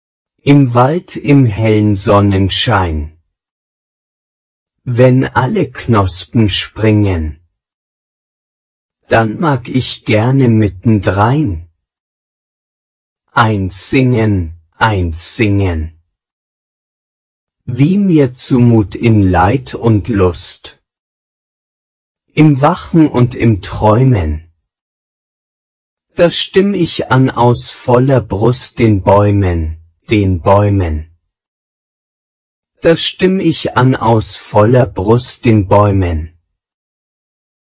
voix homme(1-21)
im-wald-prononce-garcon-1-21.mp3